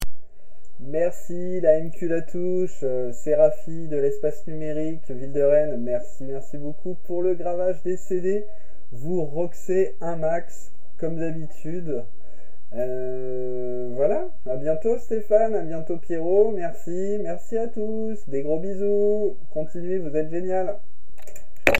Cabine de témoignages
Témoignage du 27 juin 2025 à 16h54